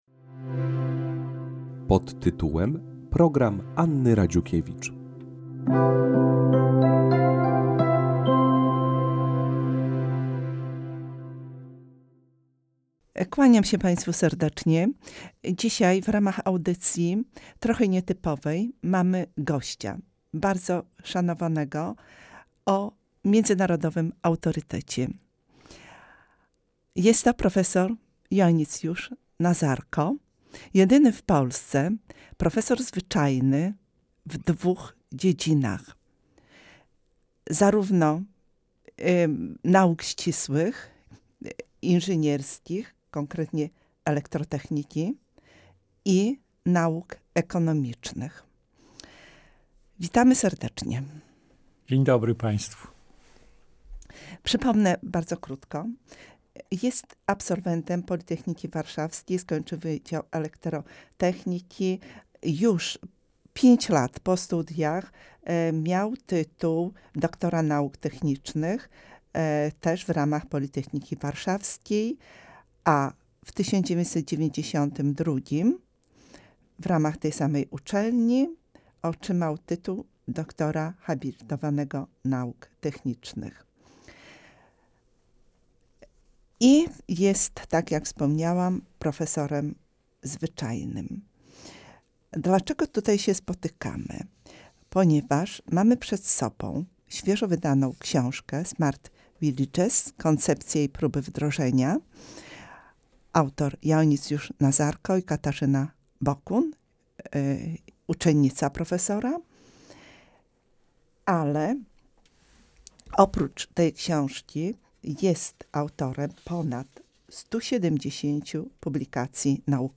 Radio Orthodoxia Rozmowa